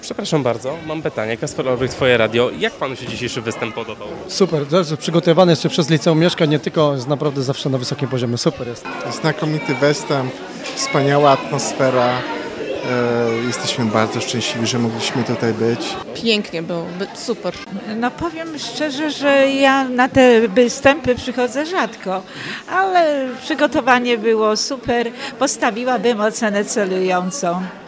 Po trwających niecałe trzy godziny występach zapytaliśmy widzów o ich odczucia.